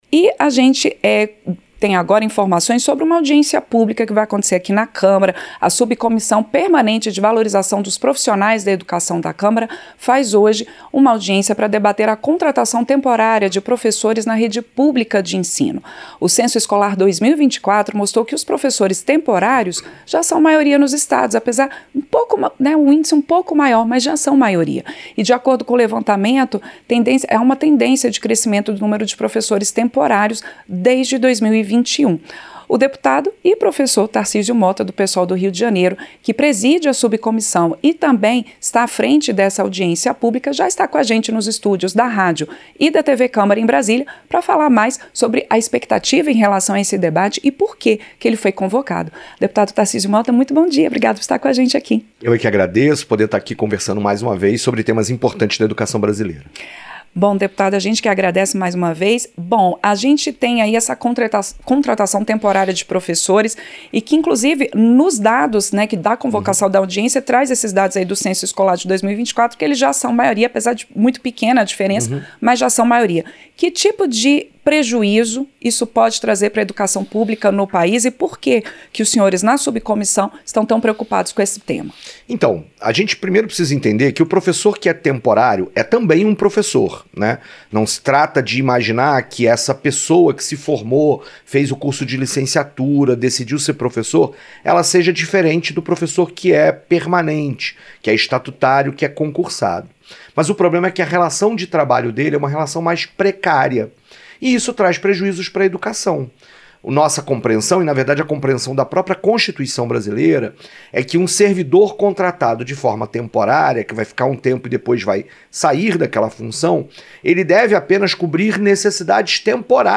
Entrevista - Dep. Tarcísio Motta (Psol-RJ)